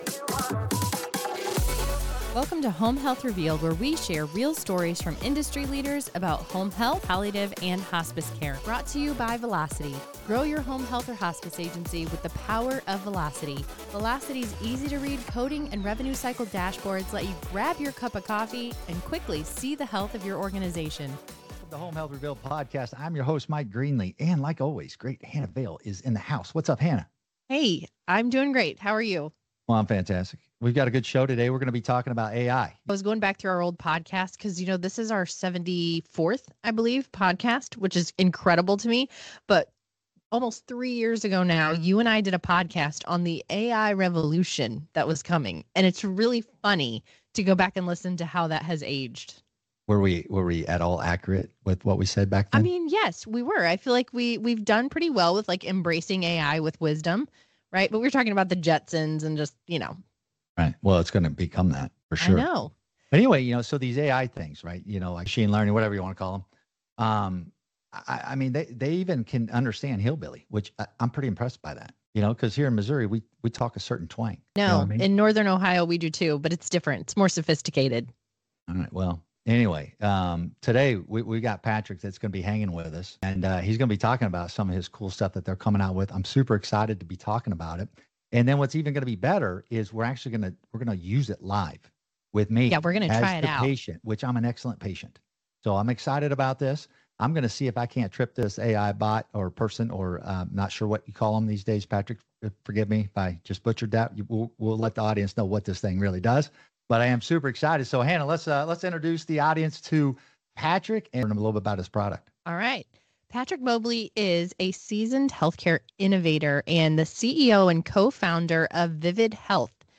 Plus, stick around for a live demo of their interactive patient calling feature, showcasing the future of care managem